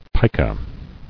[pi·ka]